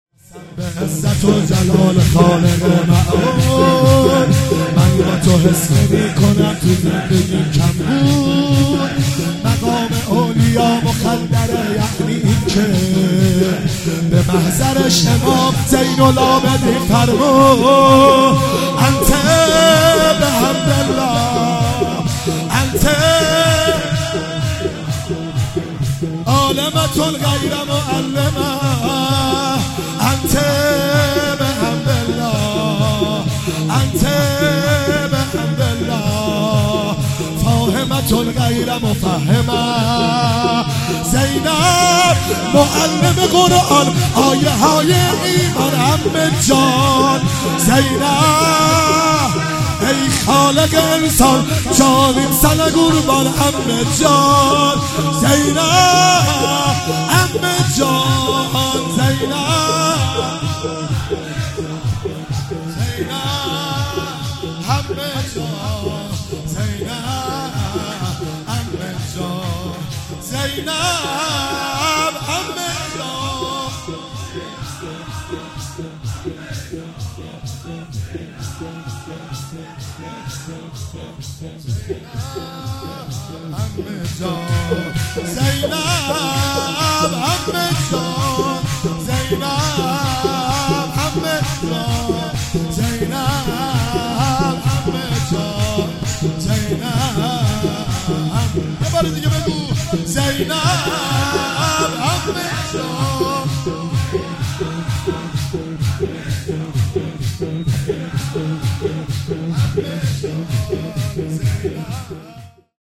شب چهارم محرم 96 - شور - قسم به عزت و جلال خالق معبود